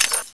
chain.wav